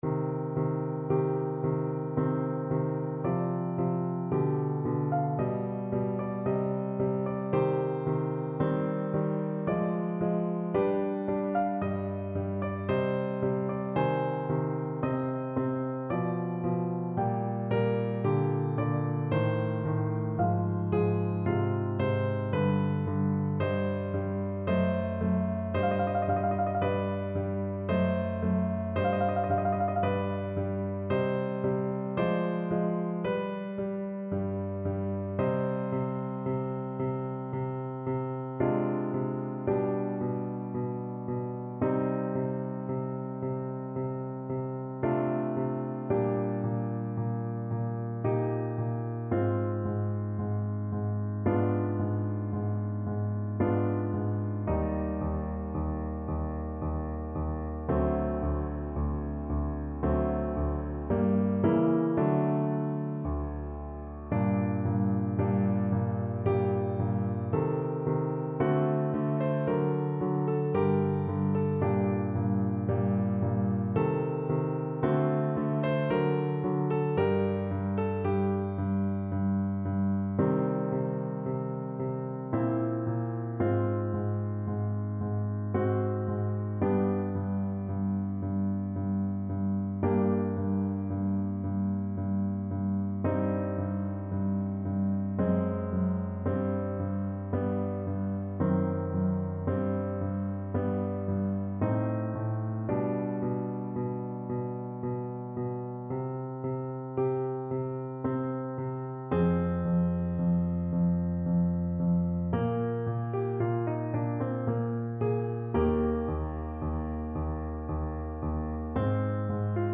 Classical Vivaldi, Antonio Stabat Mater, RV 621, 1st Movement French Horn version
Play (or use space bar on your keyboard) Pause Music Playalong - Piano Accompaniment Playalong Band Accompaniment not yet available transpose reset tempo print settings full screen
C minor (Sounding Pitch) G minor (French Horn in F) (View more C minor Music for French Horn )
3/4 (View more 3/4 Music)
= 56 Largo
Classical (View more Classical French Horn Music)